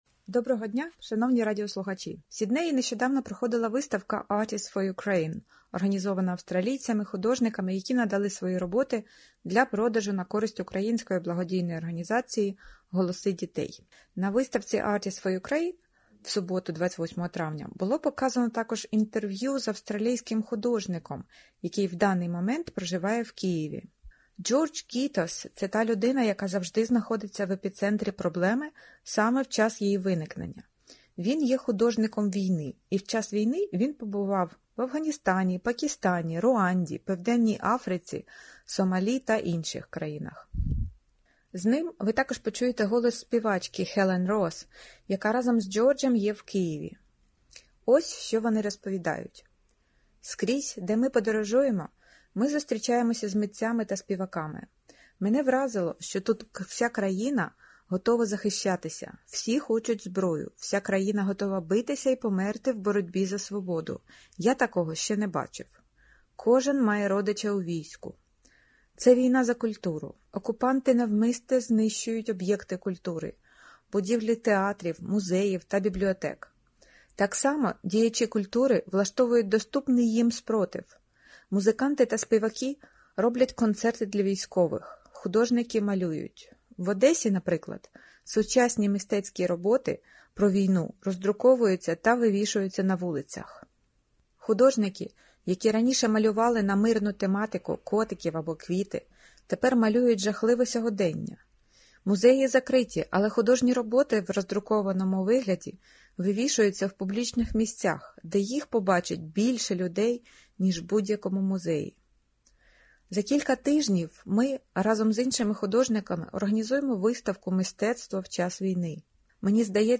Зараз вони знаходяться в Україні. Свої враження про сучасну Україну вони розповіли через відеозв'язок...